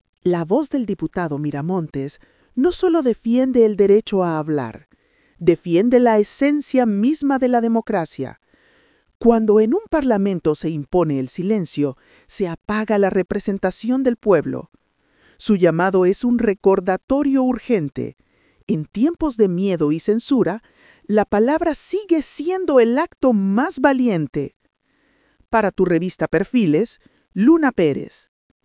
🎙 COMENTARIO EDITORIAL